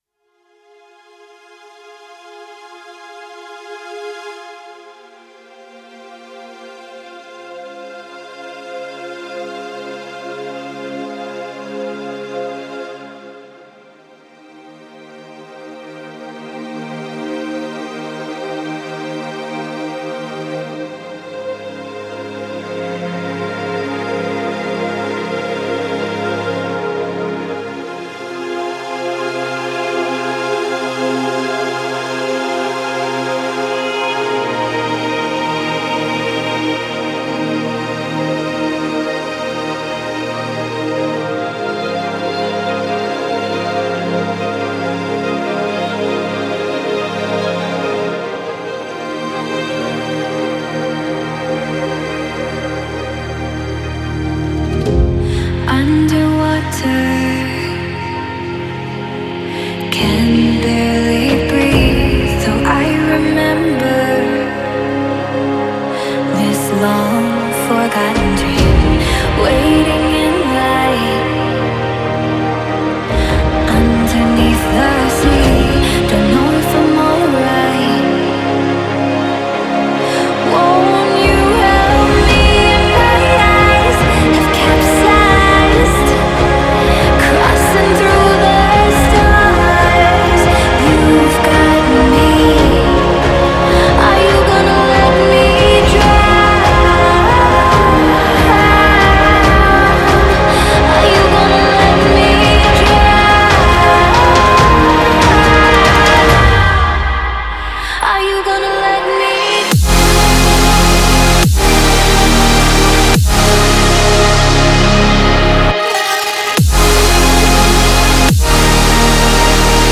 EDM, Electronic, Melodic Dubstep
Dramatic, Dreamy, Epic, Ethereal
Vocals